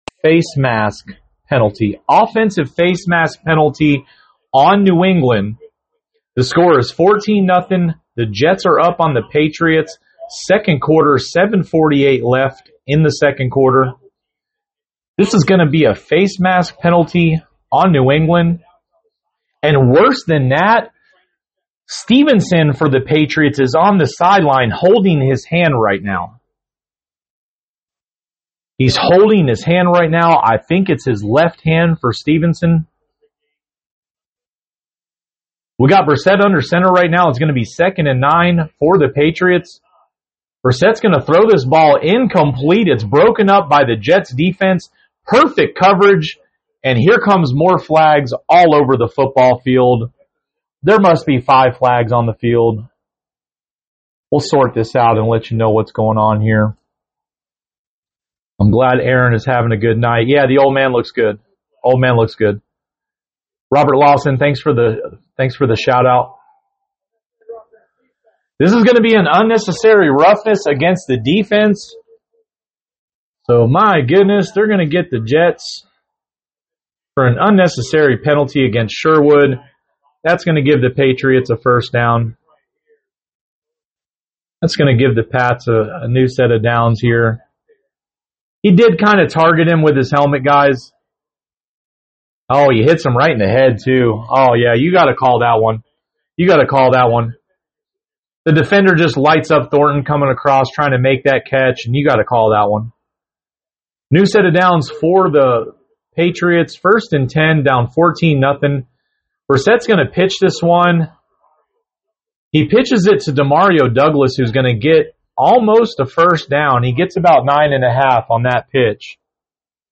Play by Play NFL Patriots at Jets Live Broadcast 9.19.24 Week 3